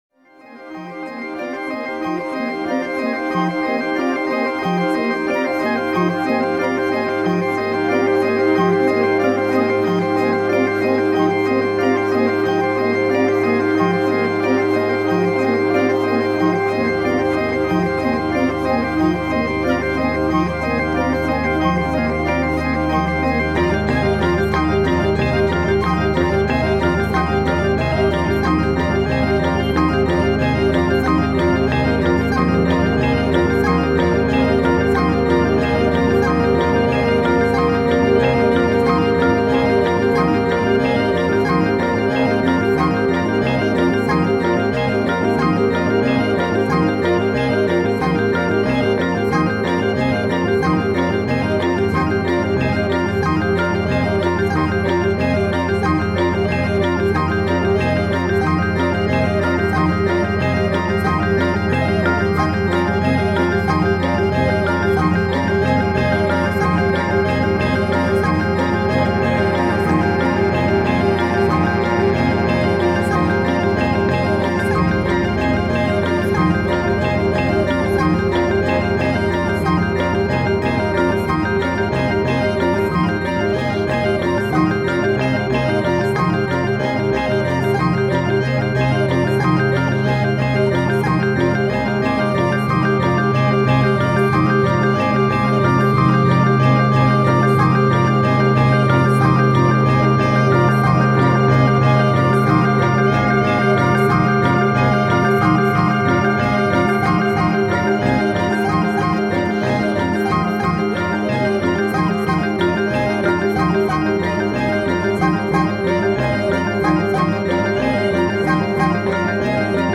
minimalist composition